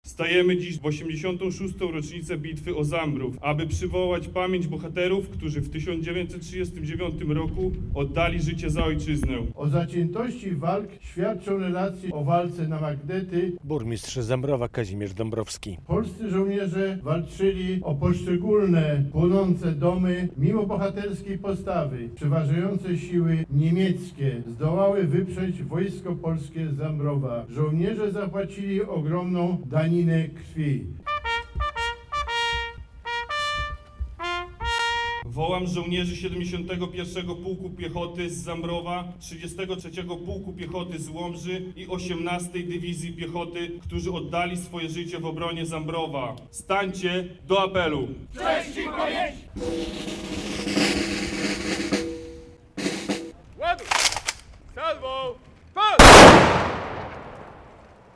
Uroczystości miały wojskową oprawę - składaniu kwiatów i okolicznościowym przemówieniom towarzyszył apel pamięci i salwa w wykonaniu kompanii honorowej 18. Łomżyńskiego Pułku Logistycznego.